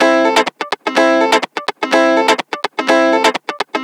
Electric Guitar 10.wav